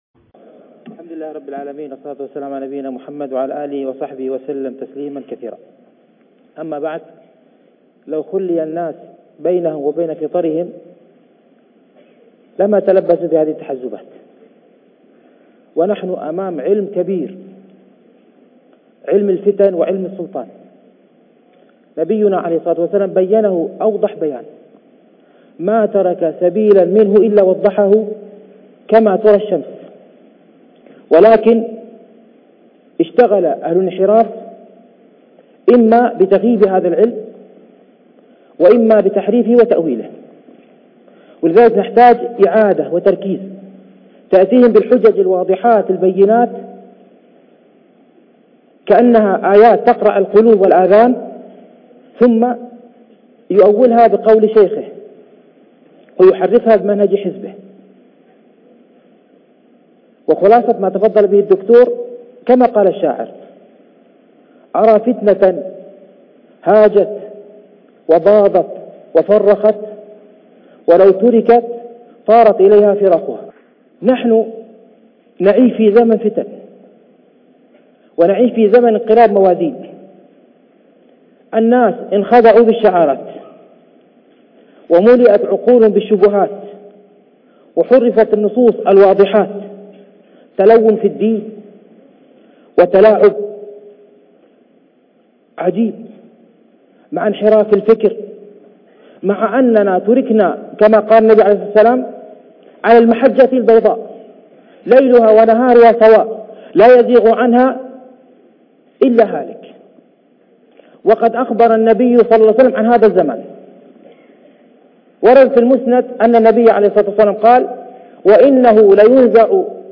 MP3 Mono 11kHz 32Kbps (CBR)